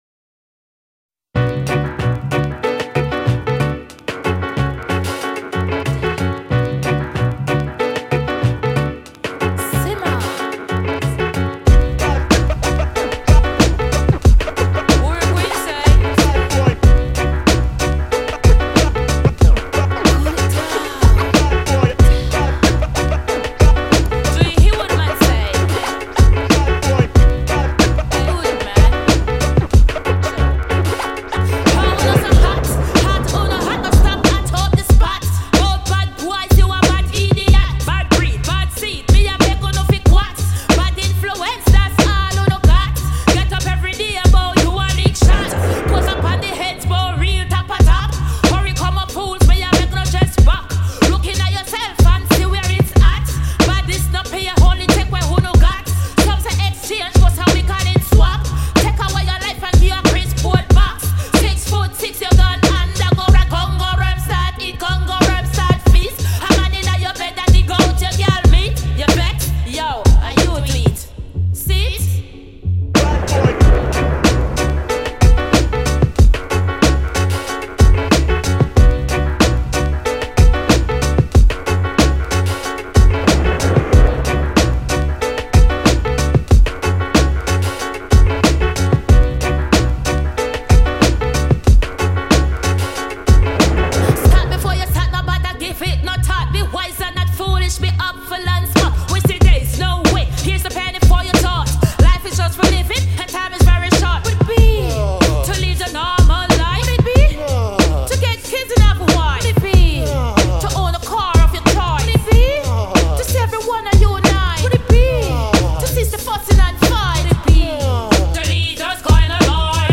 dancehall , reggae